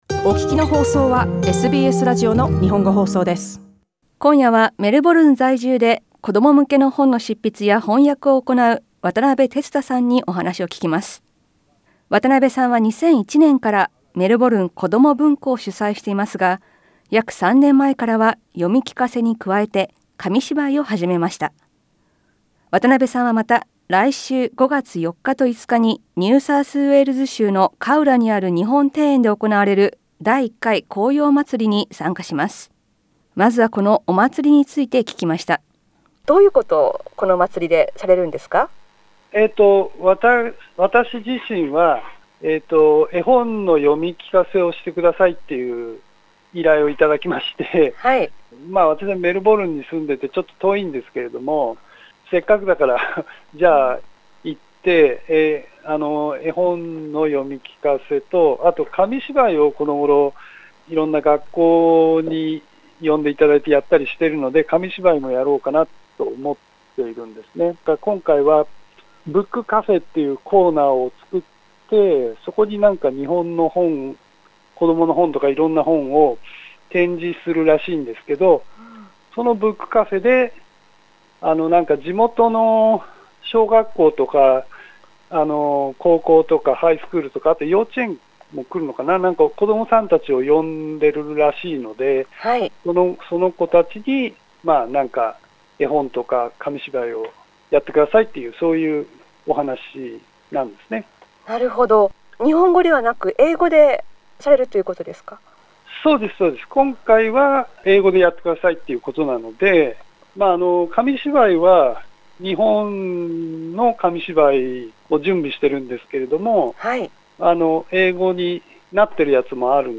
インタビューでは紅葉祭りについてや、紙芝居の魅力、そして読み聞かせや紙芝居をする際のアドバイスなどを聞きました。